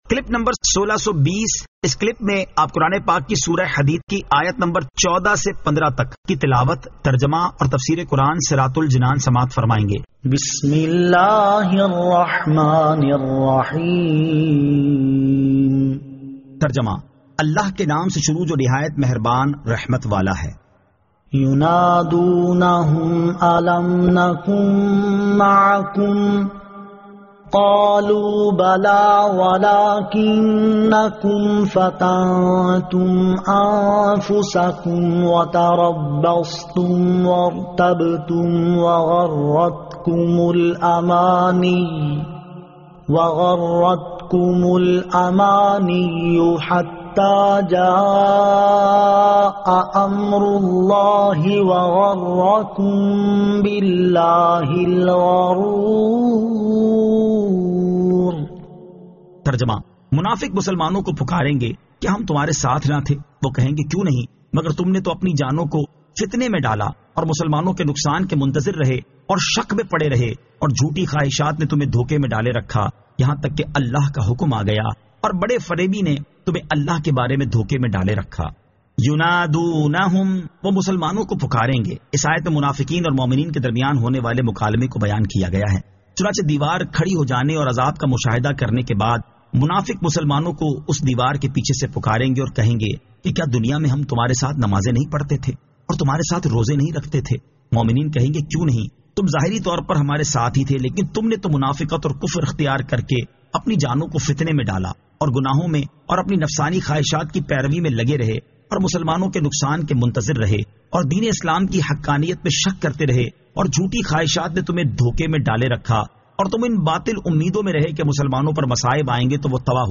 Surah Al-Hadid 14 To 15 Tilawat , Tarjama , Tafseer